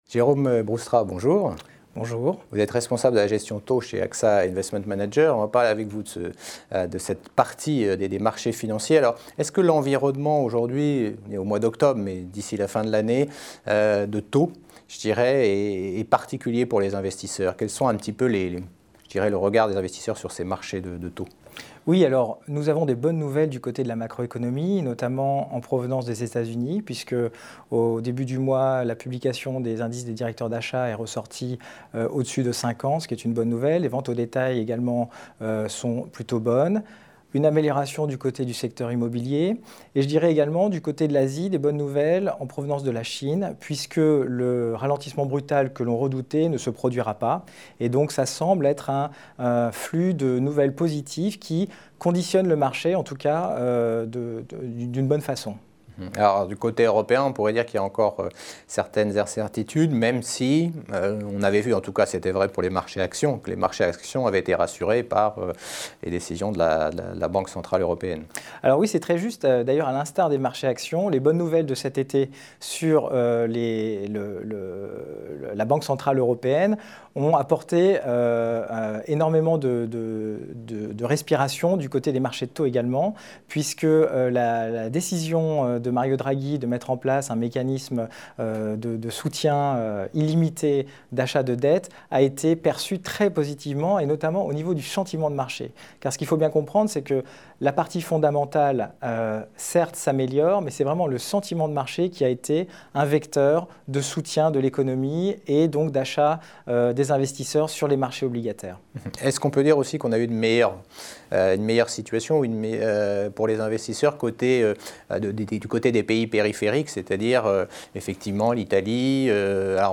Marchés obligataires : Interview